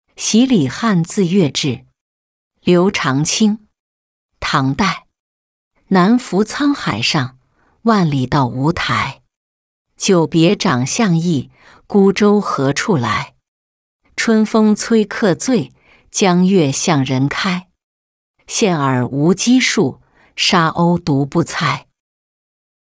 喜李翰自越至-音频朗读